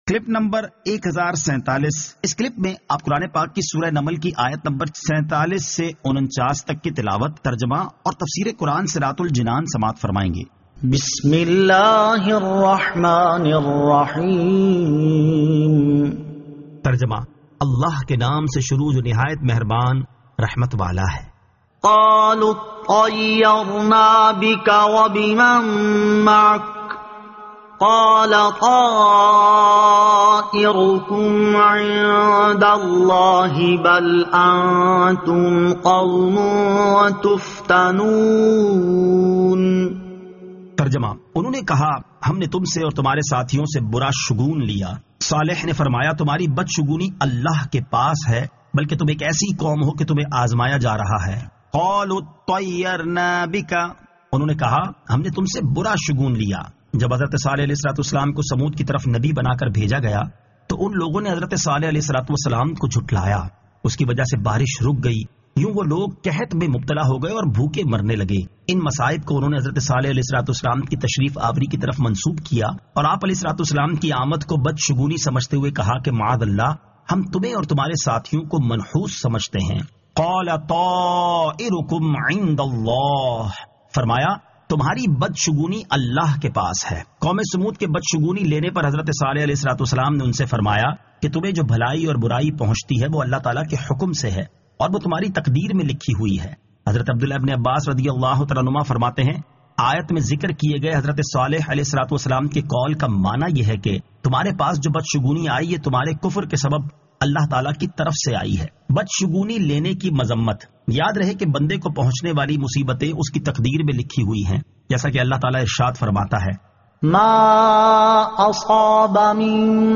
Surah An-Naml 47 To 49 Tilawat , Tarjama , Tafseer